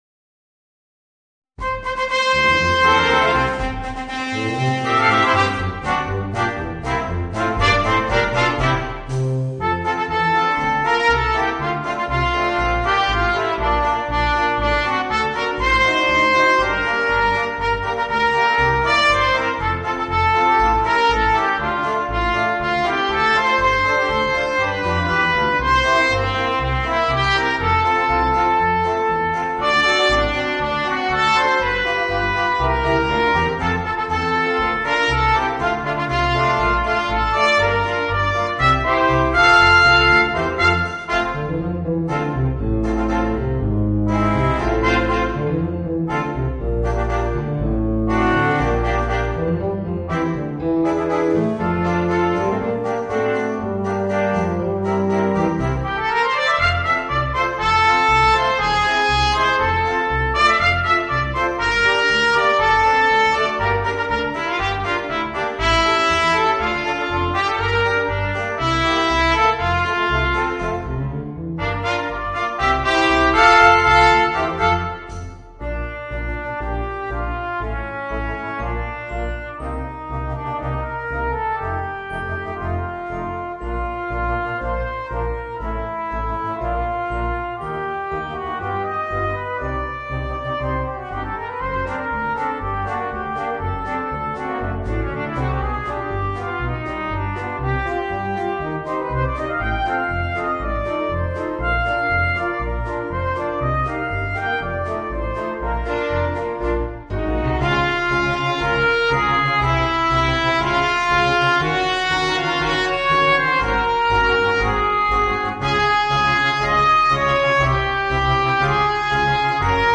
Voicing: 2 Trumpets, Horn, Trombone and Drums